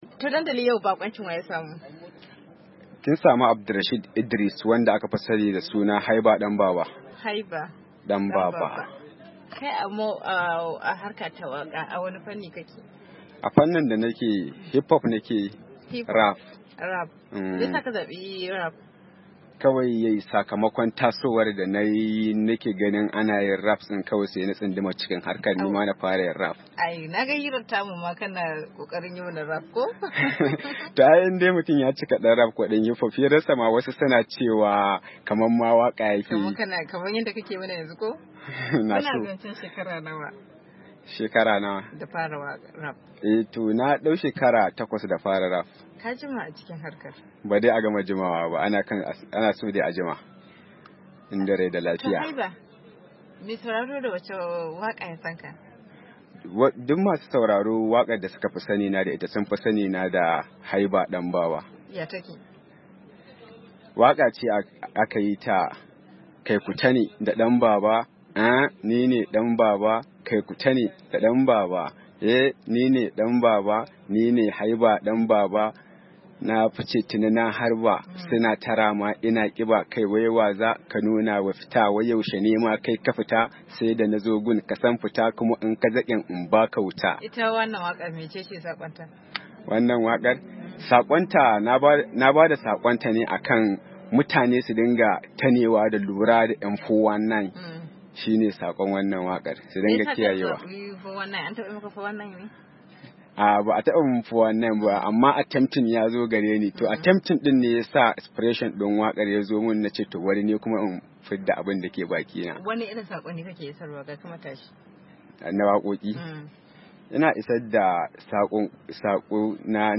Yayin wata hira da ya da Dandali